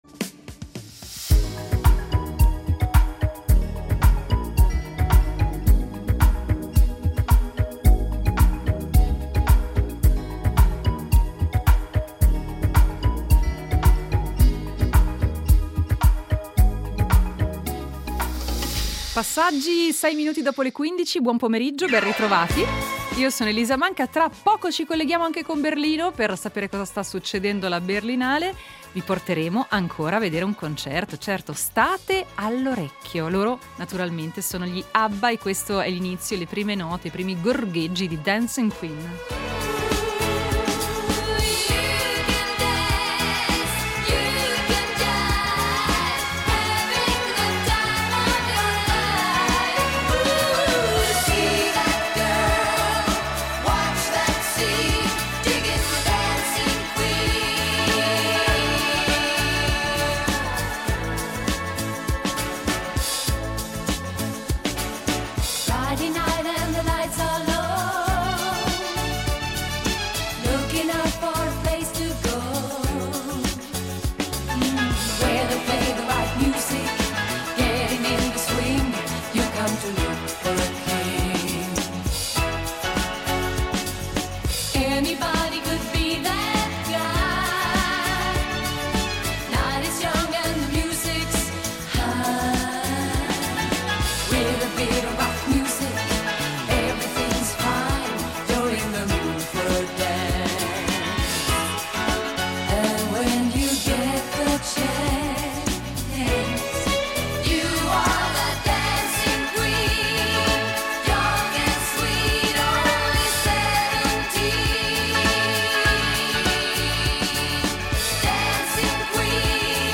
In diretta dal Festival internazionale del cinema di Berlino